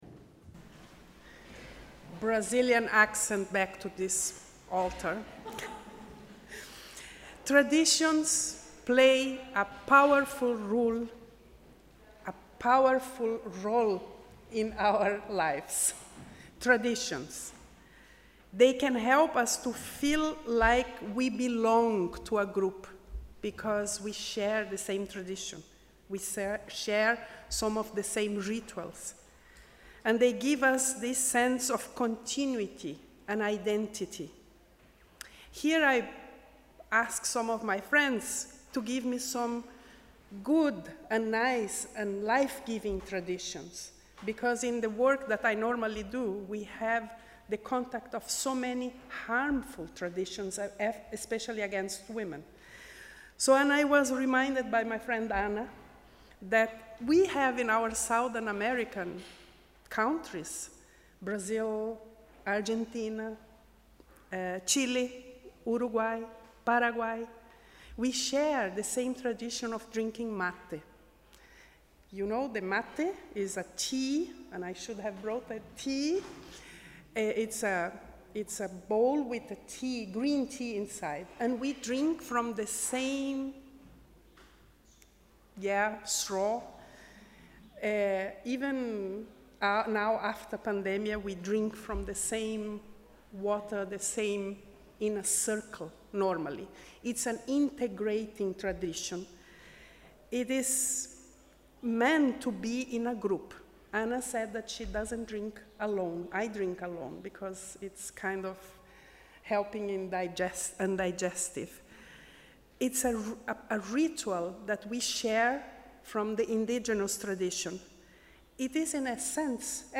Sermon: ‘Life-giving traditions’